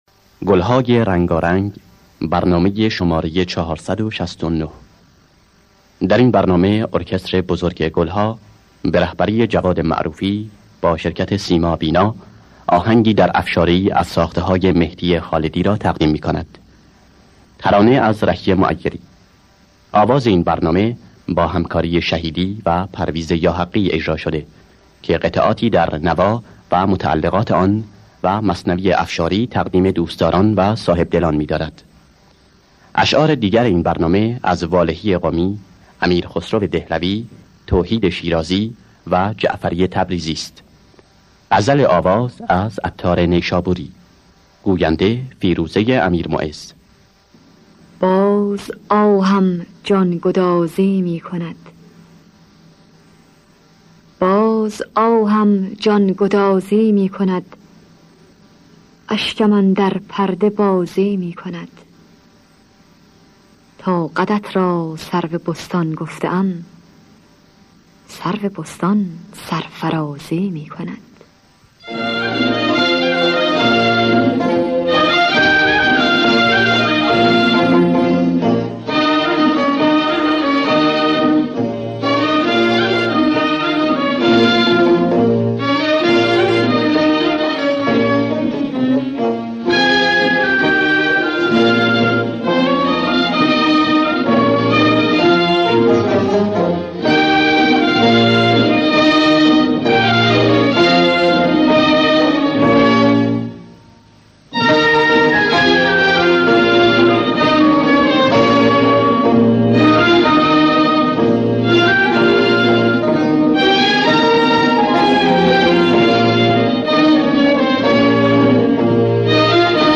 در دستگاه افشاری